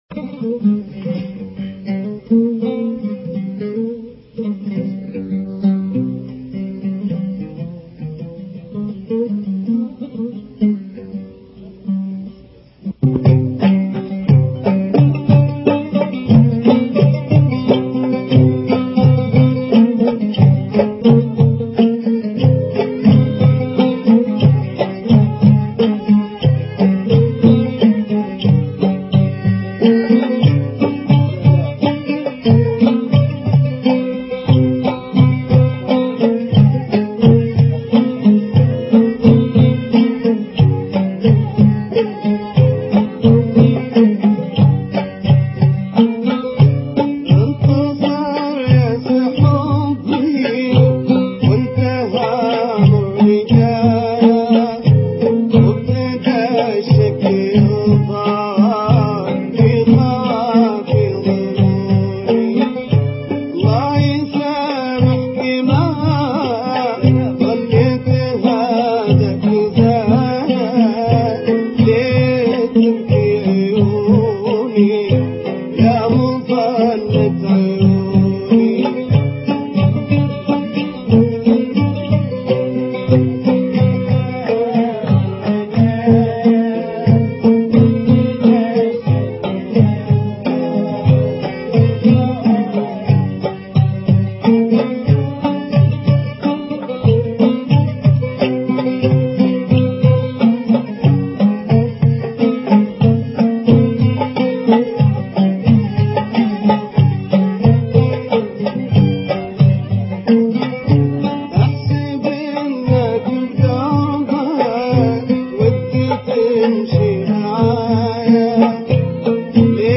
يغني في حفلة أبها